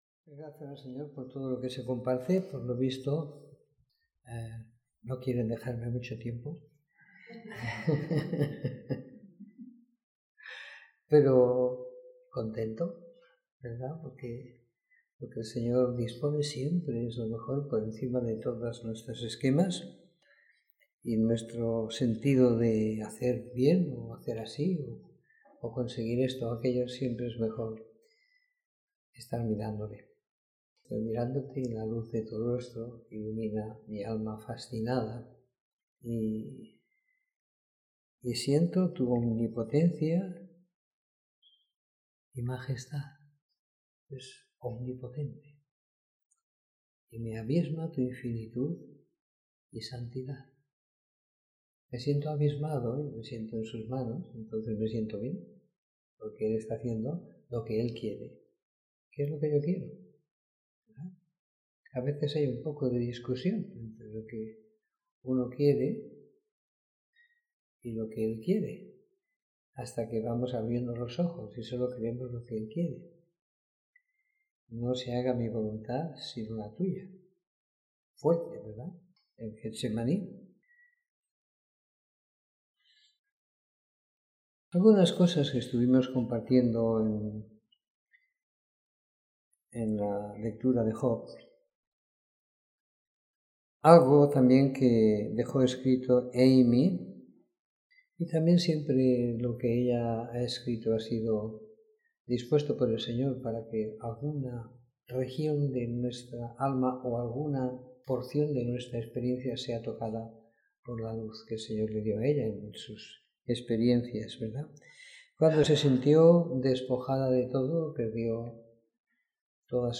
Reunión semanal de compartir la Palabra y la Vida.